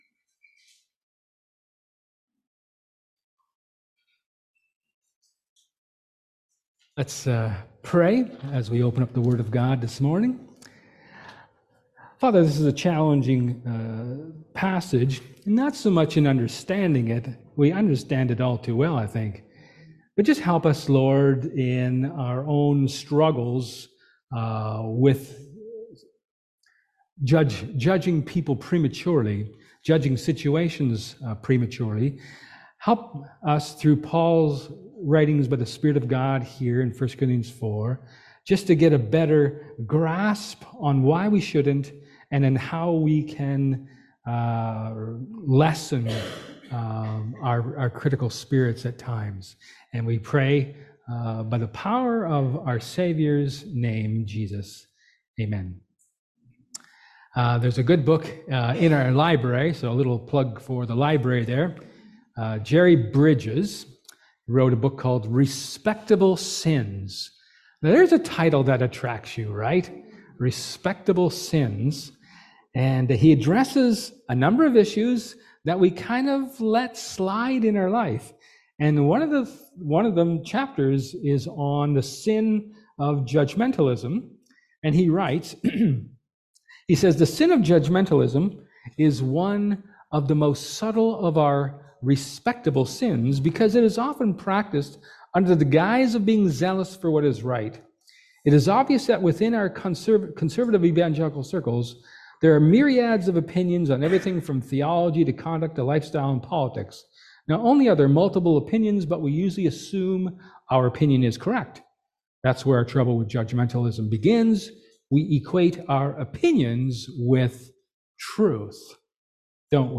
John 4:27-42 Service Type: Sermon